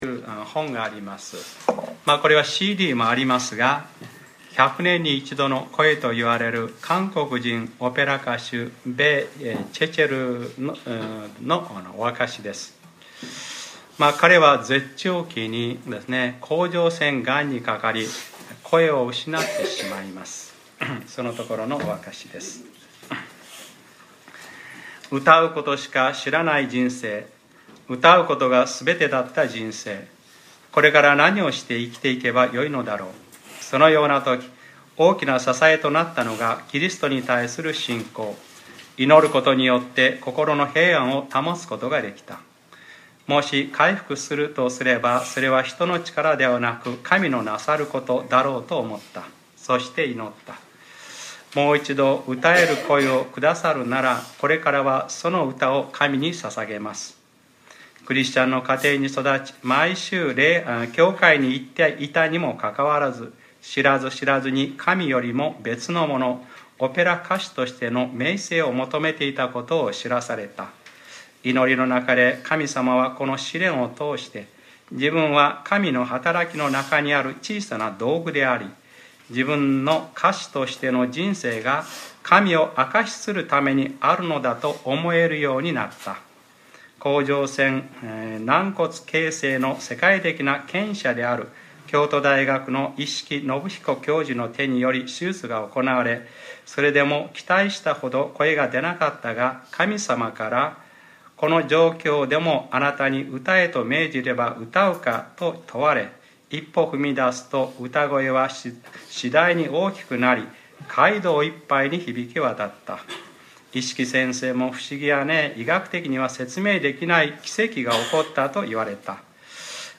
2014年5月25日（日）礼拝説教 『黙示録ｰ２９：その方は血に染まった衣を着ていて』